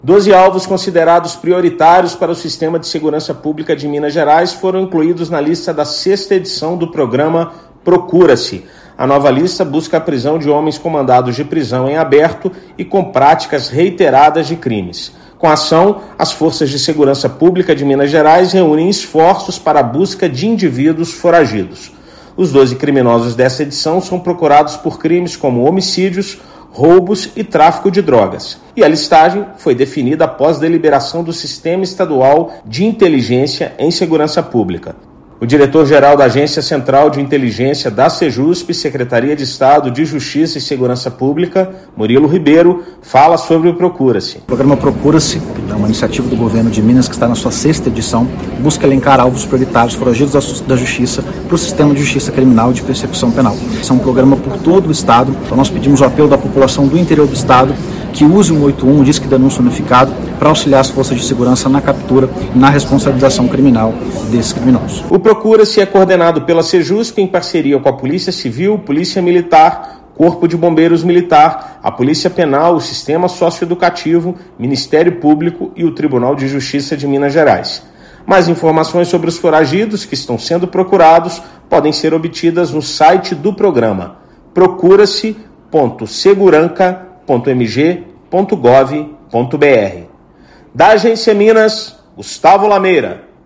[RÁDIO] Segurança Pública lança sexta edição da lista dos criminosos mais procurados do estado
Nova lista do programa Procura-se busca a prisão de homens com mandados de prisão em aberto e fichas criminais com práticas reiteradas de crimes. Ouça matéria de rádio.